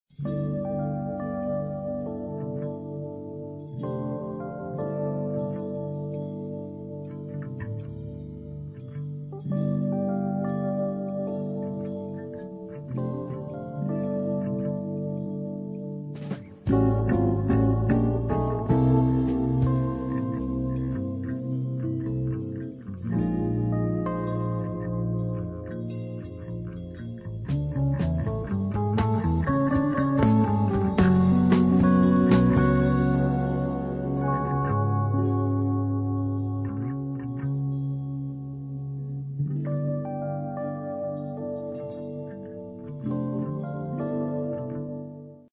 jazzrock